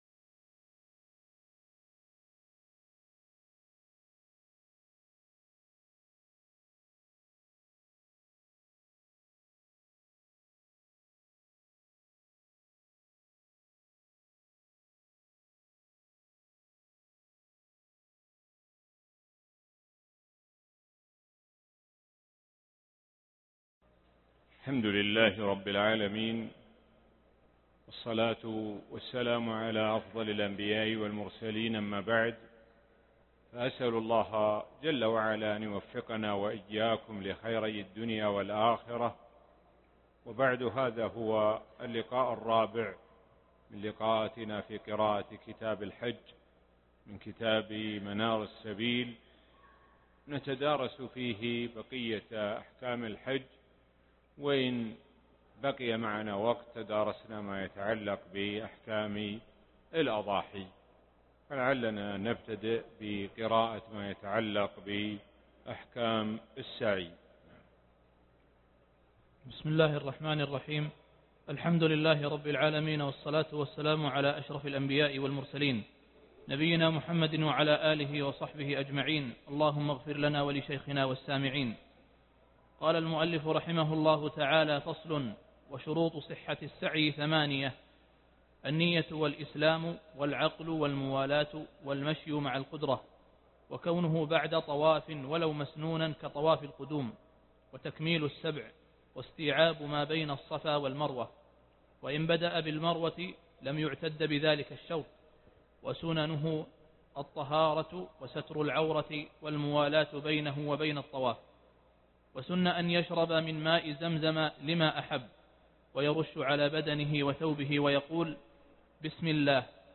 الدرس الرابع (3 ذي الحجة 1436هـ) القواعد الفقهية والأصولية المتعلقة بكتاب الحج